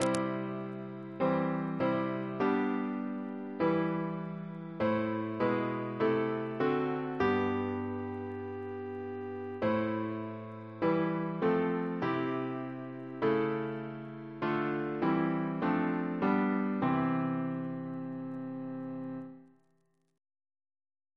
Double chant in C Composer: Martin Luther (1483-1546) Reference psalters: ACB: 127; ACP: 98; CWP: 37; H1940: 695; H1982: S283; OCB: 1; PP/SNCB: 97; RSCM: 92